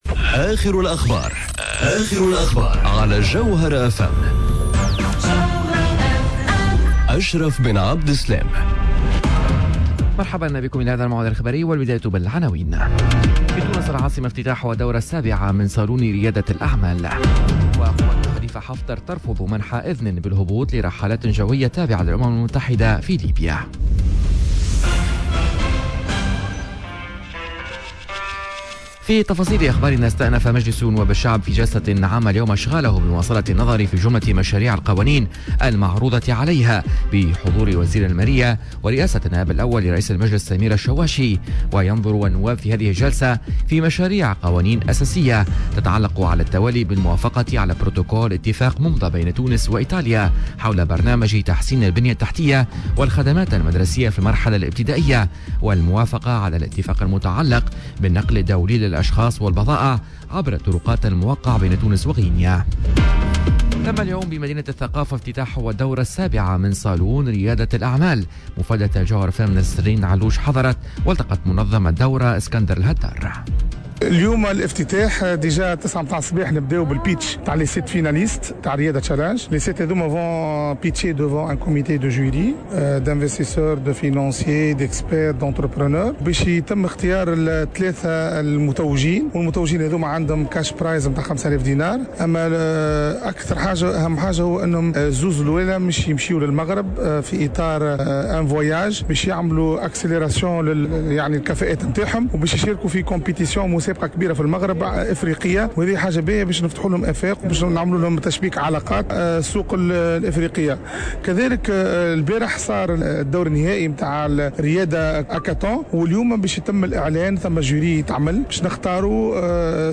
نشرة أخبار منتصف النهار ليوم الإربعاء 12 فيفري 2020